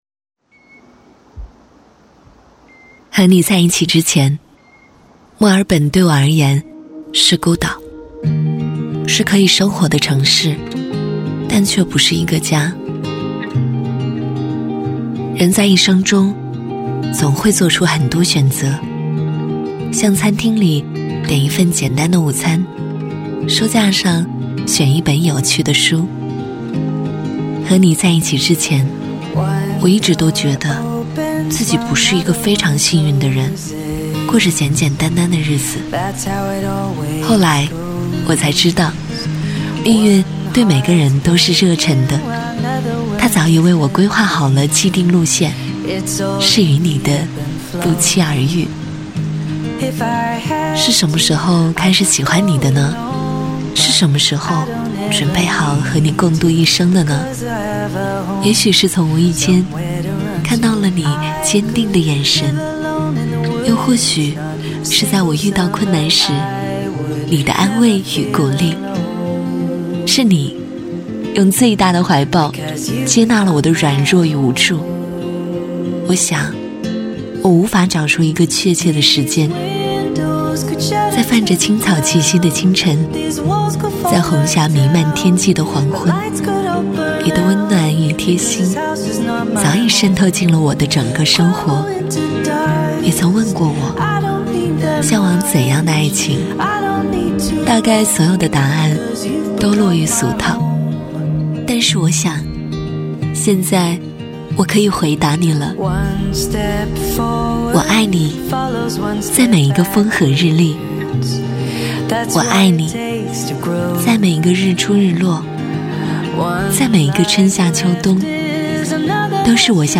女国126_其他_微电影_爱情独白 我爱你定.mp3